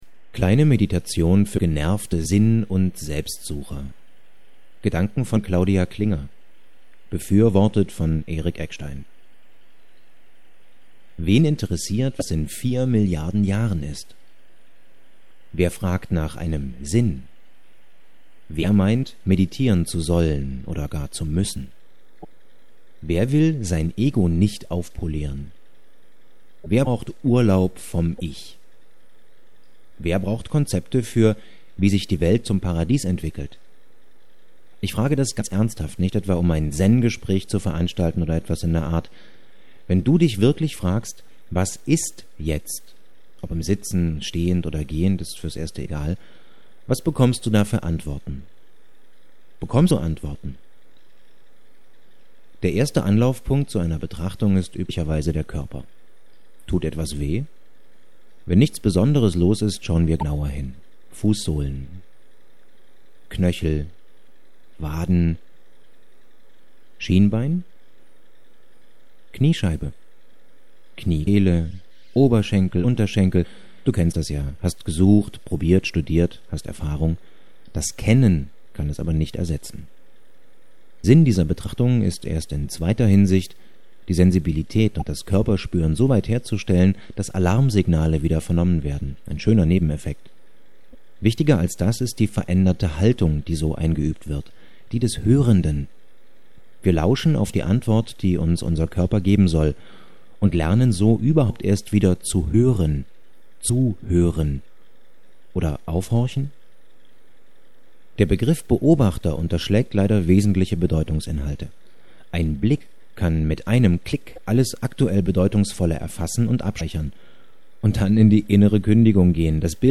Meditations-Anleitung.mp3